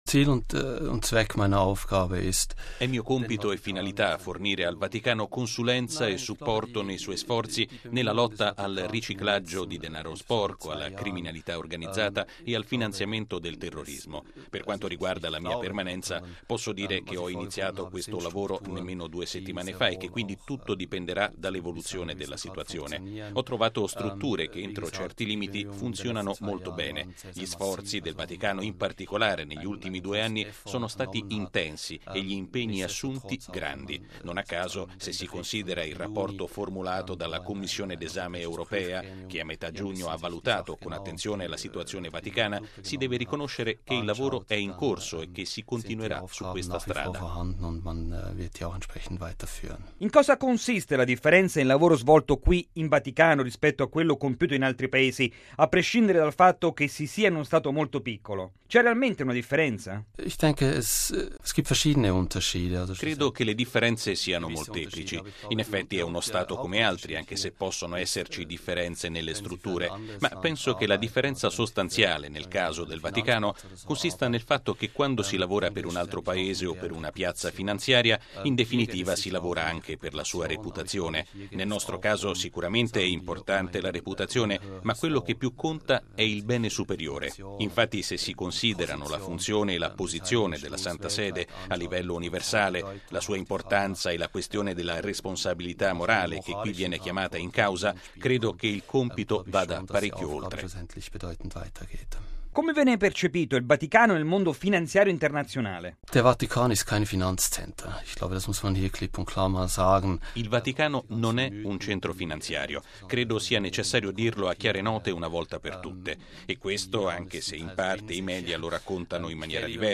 lo ha intervistato domandandogli di illustrare anzitutto le sue mansioni in ambito vaticano